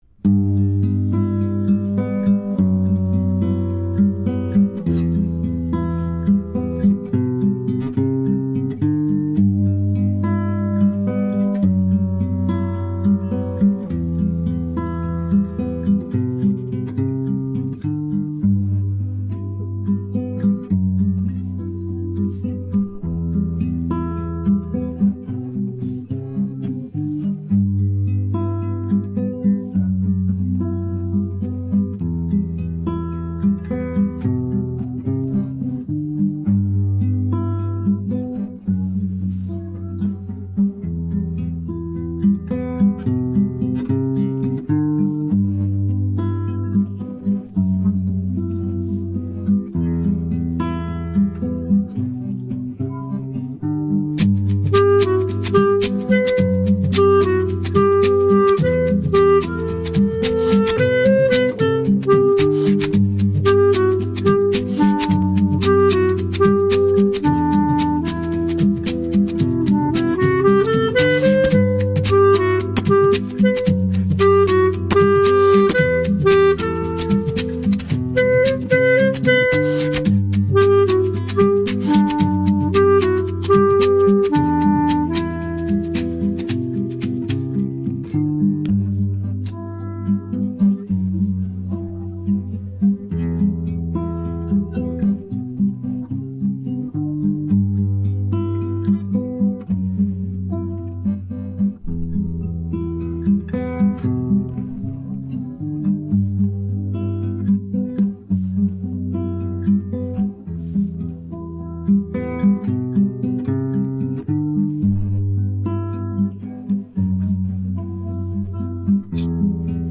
rock караоке 39